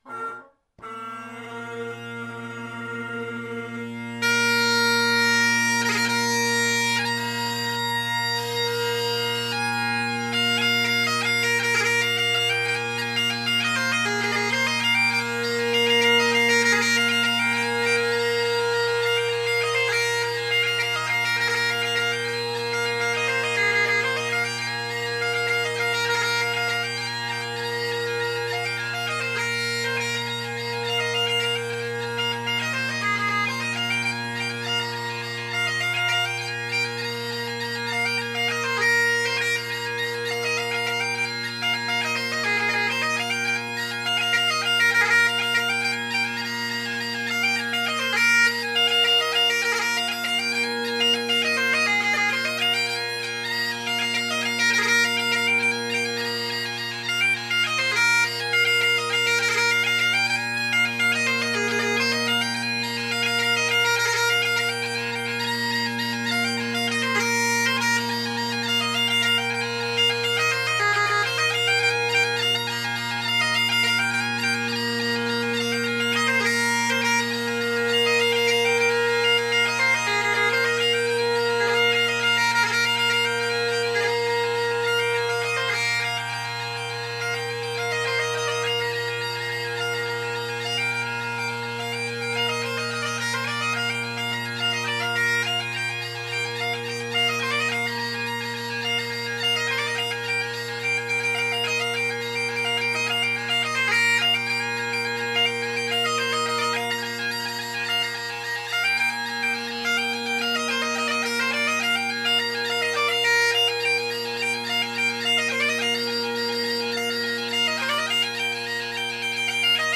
Great Highland Bagpipe Solo, Reviews
Out of Redwood, Henderson Harmonic Deluxe, Ezeedrone, Selbie, Wygent, Kinnaird, Colin Kyo, and Crozier cane we settled on Redwood tenors and a Selbie bass in the Marr’s. The Redwood tenors are very bright and solid while the Selbie bass gave a very deep and broad bass tone.
So what follows are the same tunes played on the same Marr bagpipe with the same drone and chanter reeds, the only difference being the chanter.
Oh wait, the Gael came in at 472 Hz, the McC2 at 480 Hz.
Also, I’m walking around in a circle relative to the recorder (a Zoom H2), so you’ll hear tenor, bass, and chanter dominant parts as I walk around.
The B on the Gael was a teeny bit flat. I’ve got tape on C, D, and F. The high G is in tune when I remember to blow out the high A.